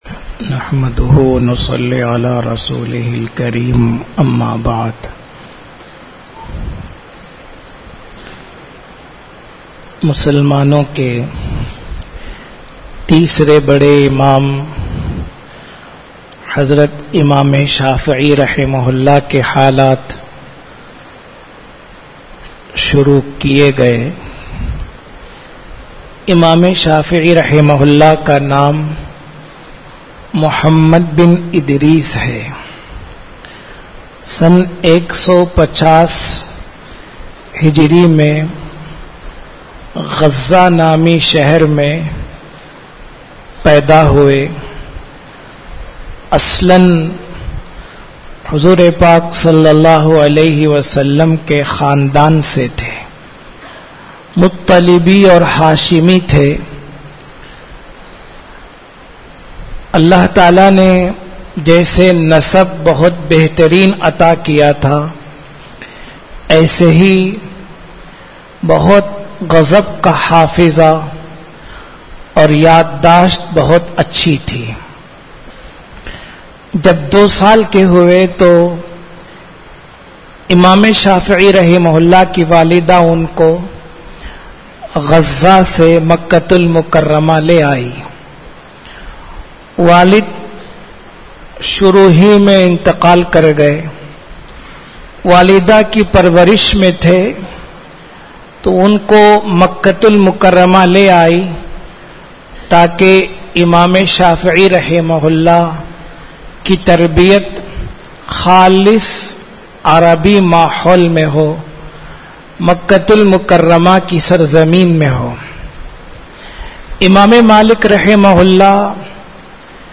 Bayaan